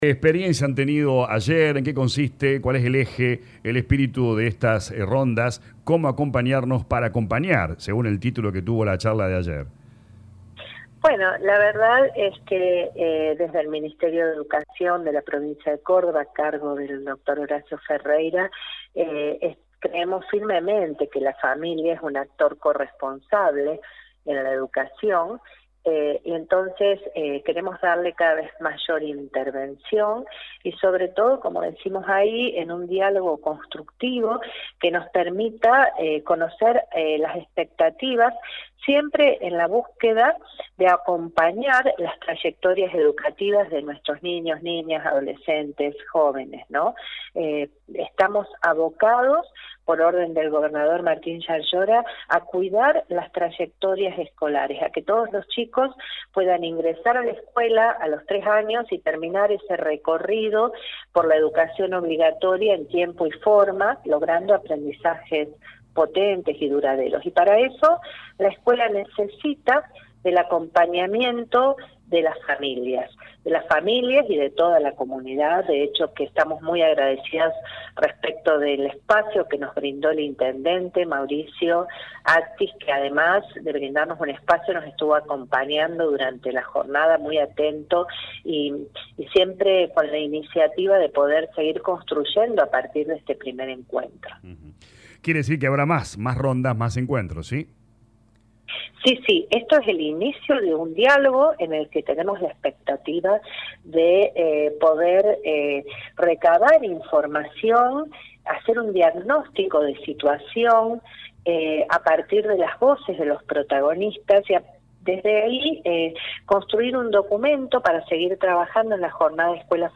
Lo informó Claudia Maine, Subsecretaría de Fortalecimiento Institucional del Ministerio de Educación del Gobierno de Córdoba, en diálogo con LA RADIO 102.9.